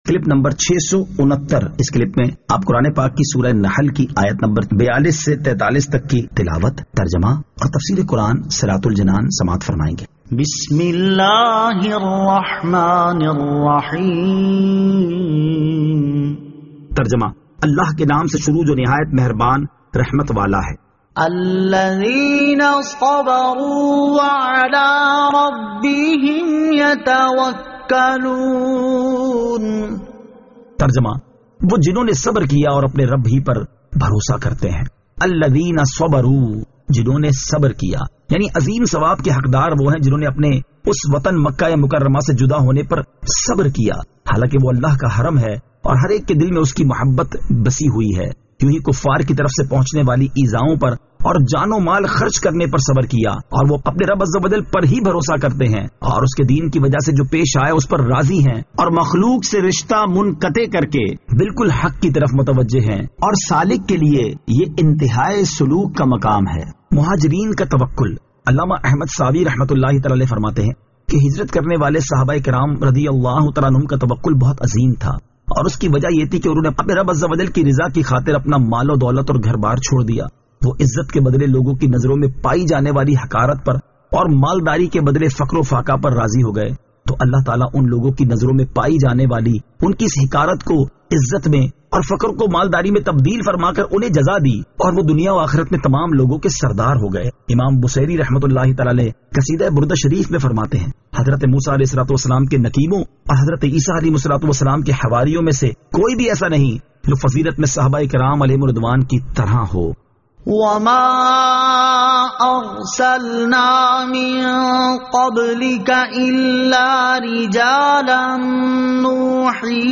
Surah An-Nahl Ayat 42 To 43 Tilawat , Tarjama , Tafseer